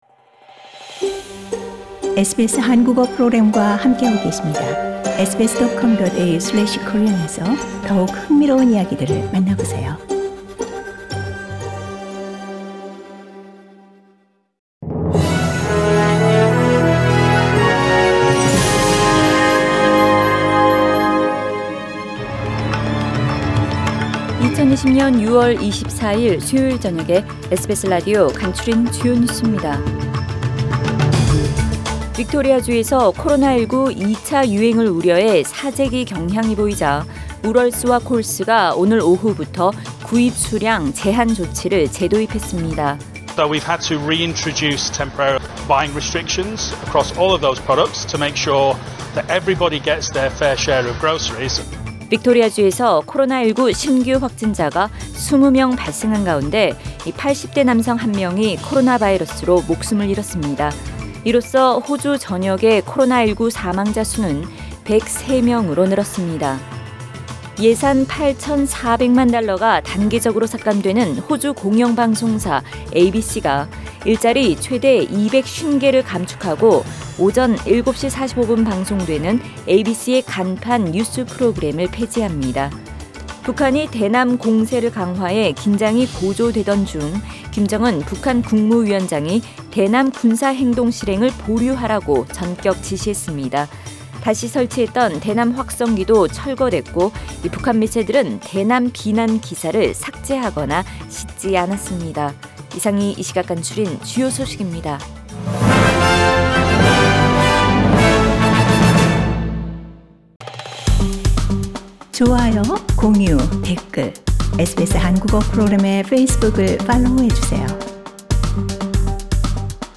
SBS 한국어 뉴스 간추린 주요 소식 – 6월 24일 수요일
2020년 6월 24일 수요일 저녁의 SBS Radio 한국어 뉴스 간추린 주요 소식을 팟 캐스트를 통해 접하시기 바랍니다.